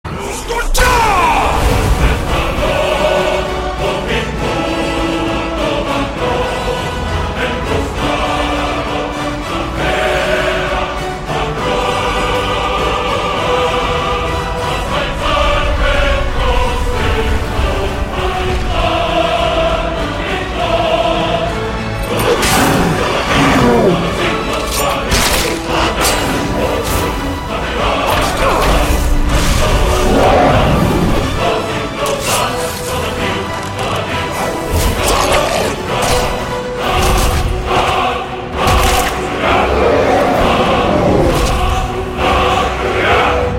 • Качество: 128, Stereo
смешные
Музыка из игры